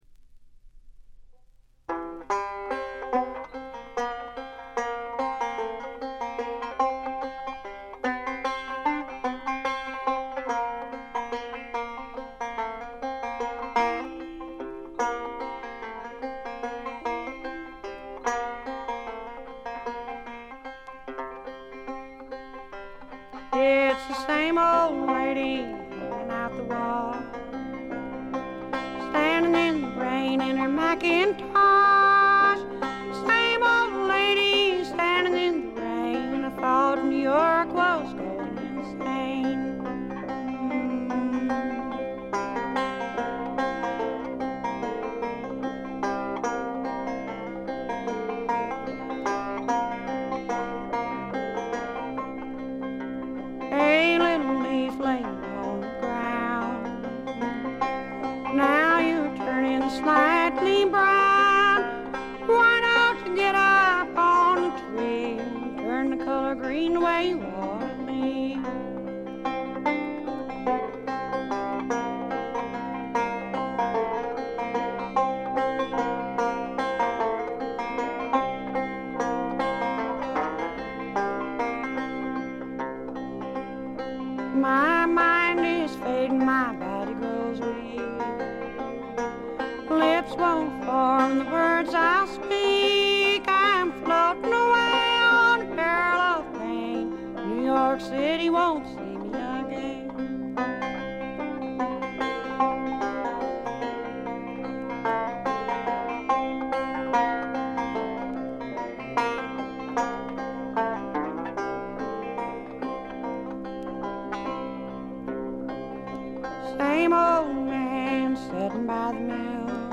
静音部での微細なチリプチが少しだけ。
試聴曲は現品からの取り込み音源です。
Vocals, Banjo, 12 String Guitar